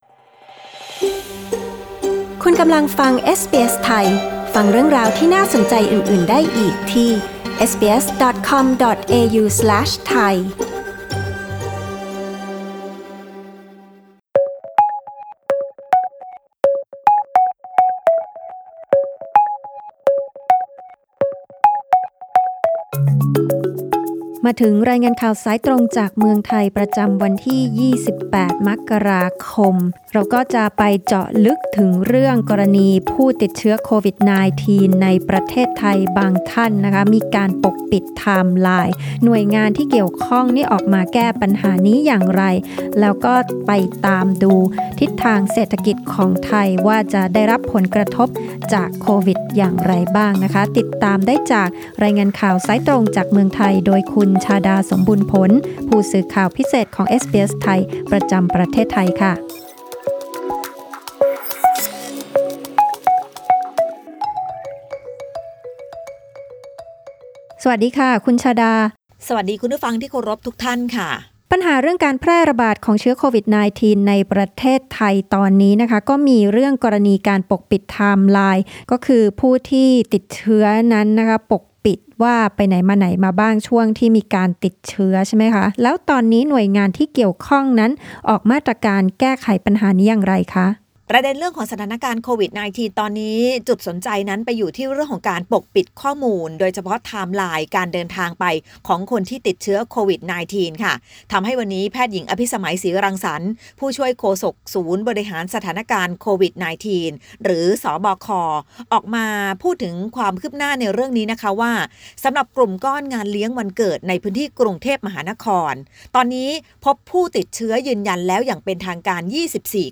รายงานข่าวสายตรงจากเมืองไทย จากเอสบีเอส ไทย Source: Pixabay